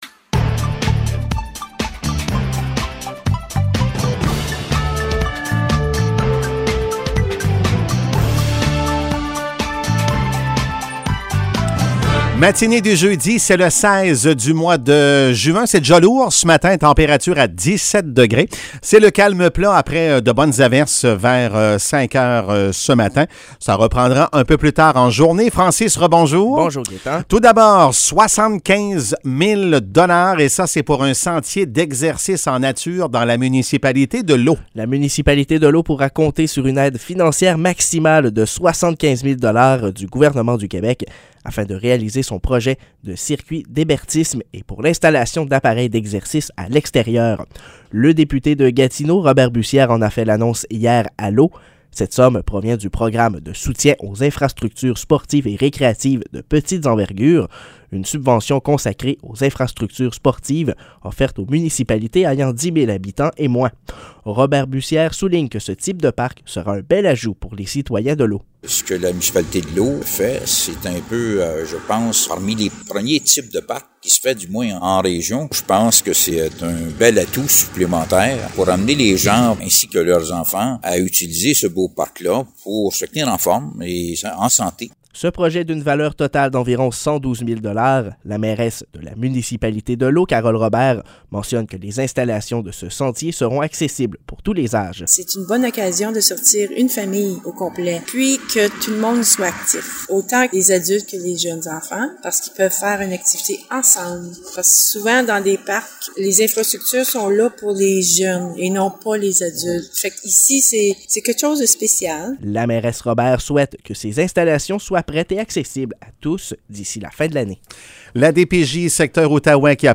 Nouvelles locales - 16 juin 2022 - 7 h